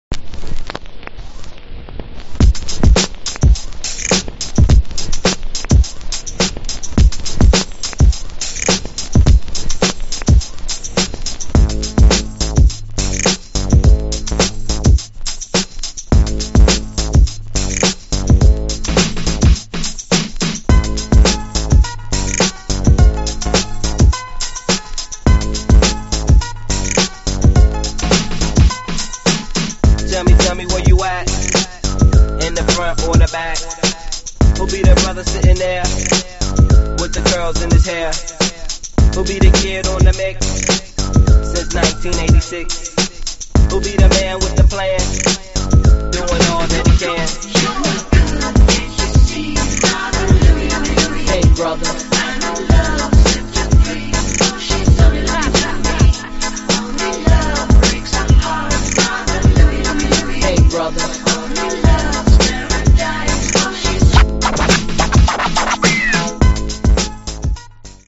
Hip Hop Rap Remix